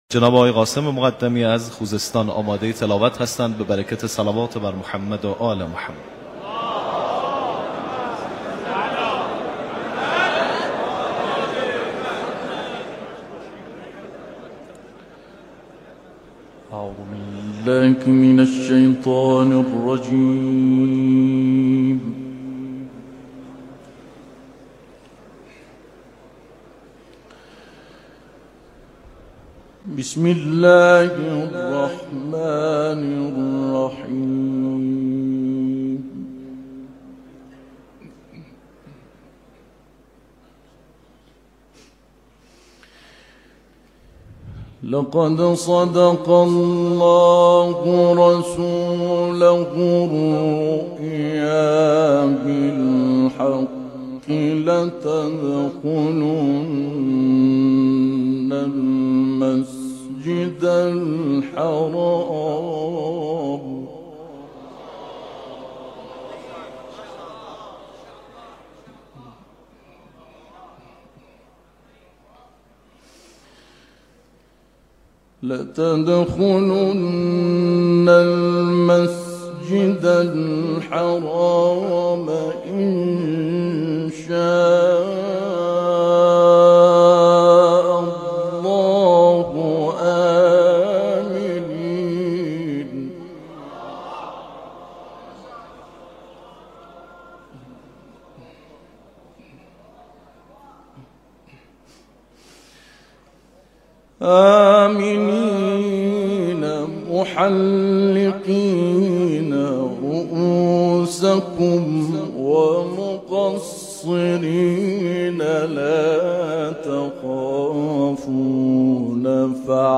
تلاوت
در محضر رهبر انقلاب